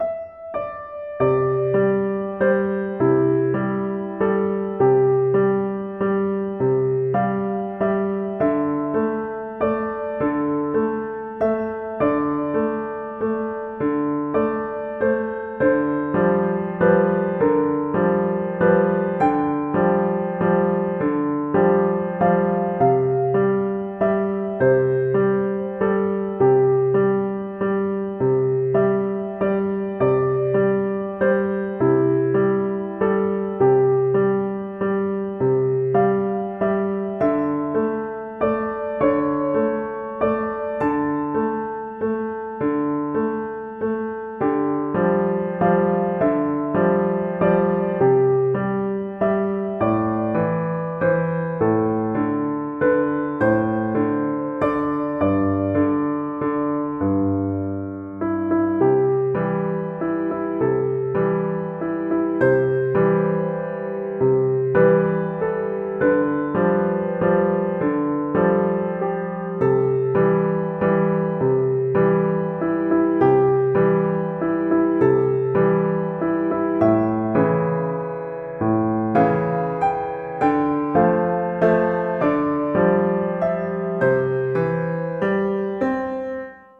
Tempo di valse
3/4 (View more 3/4 Music)
Piano  (View more Easy Piano Music)
Classical (View more Classical Piano Music)